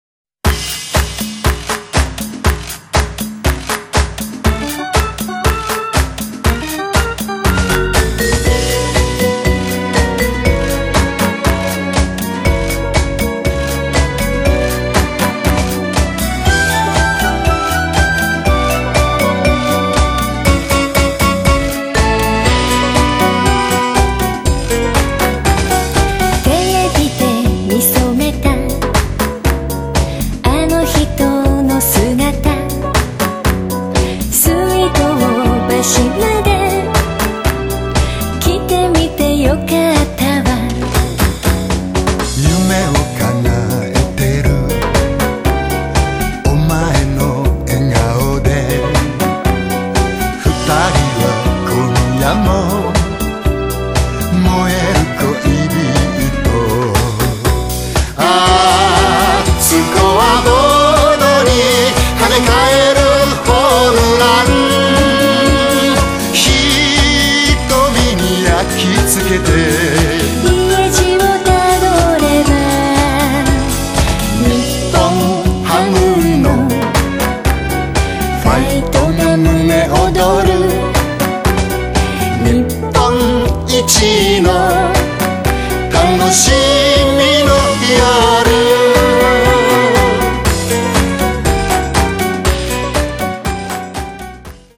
お約束のデュエット作品。